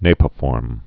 (nāpə-fôrm)